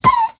Minecraft Version Minecraft Version snapshot Latest Release | Latest Snapshot snapshot / assets / minecraft / sounds / mob / wolf / puglin / hurt2.ogg Compare With Compare With Latest Release | Latest Snapshot
hurt2.ogg